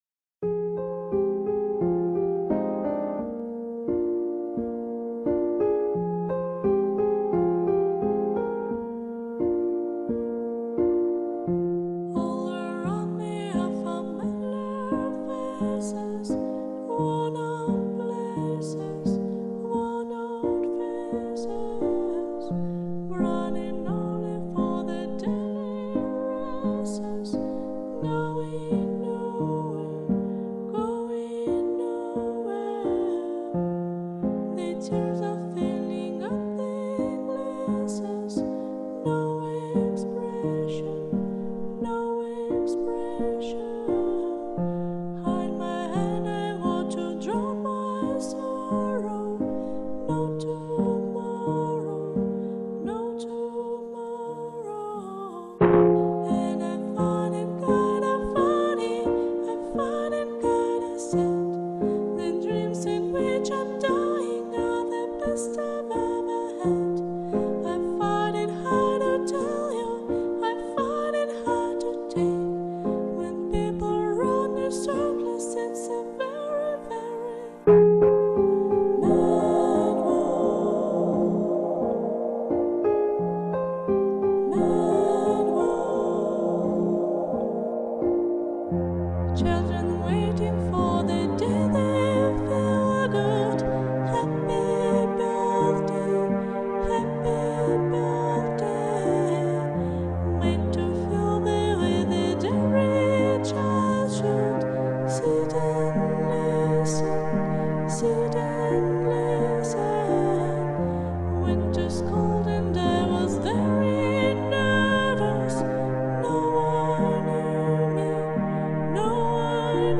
голос очень таинственно звучит, притягивяет.